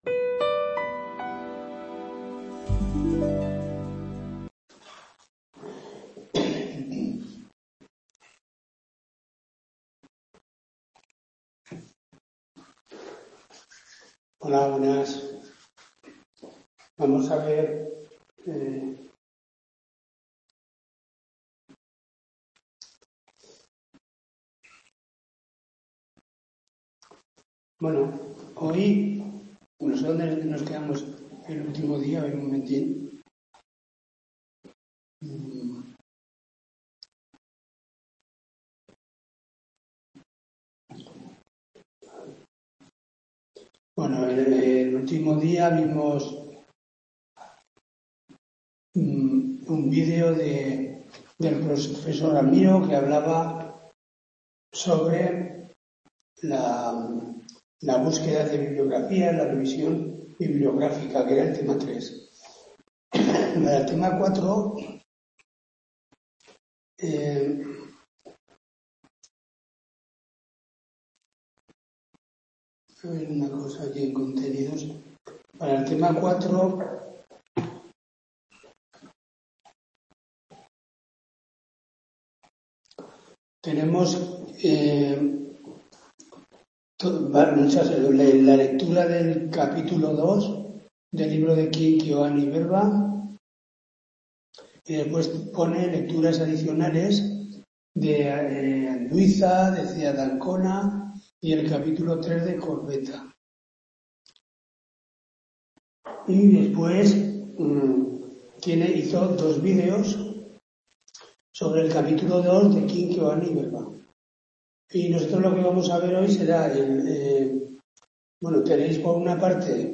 TUTORÍA DE 30/10/2024